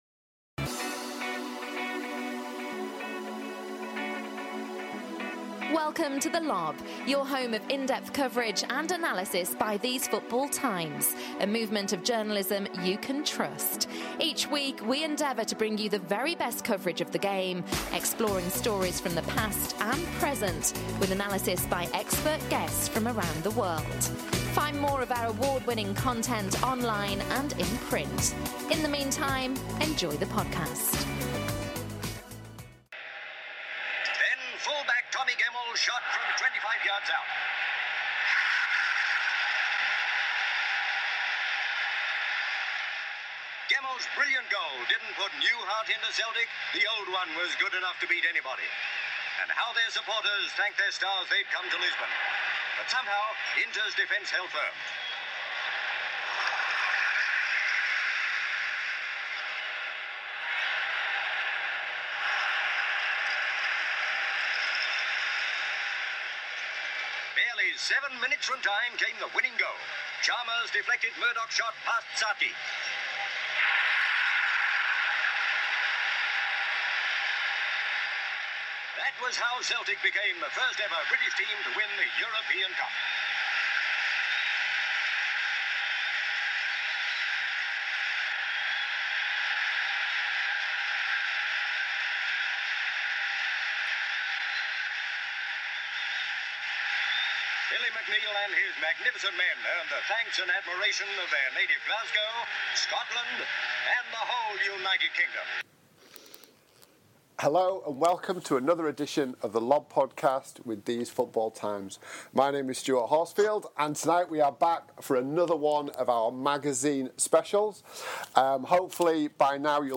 In conjunction with the Celtic magazine's release, our senior writers get together to discuss the club's history; from the early pioneers and Lions of Lisbon, to centenary glory, fall, rise again and modern dominance. This is the story of Celtic, a club like no other.